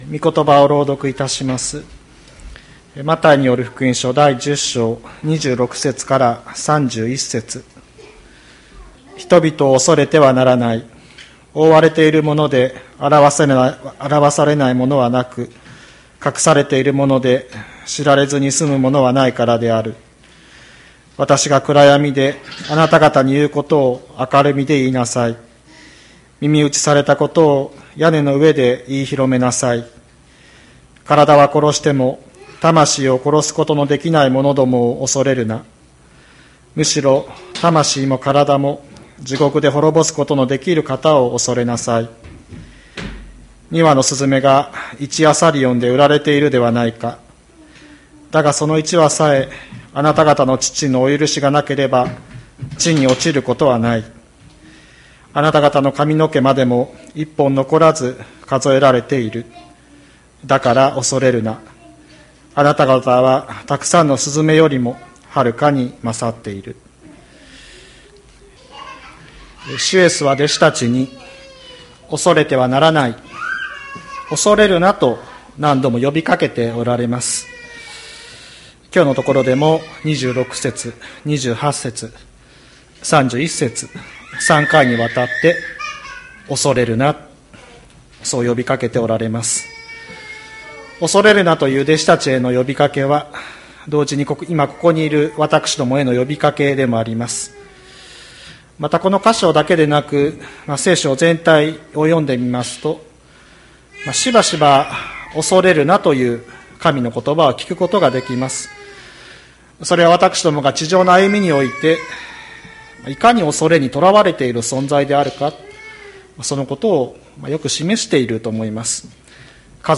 2022年02月06日朝の礼拝「何を恐れて生きていますか」吹田市千里山のキリスト教会
千里山教会 2022年02月06日の礼拝メッセージ。 主イエスは、弟子たちに「恐れてはならない」「恐れるな」と何度も呼び掛けておられます。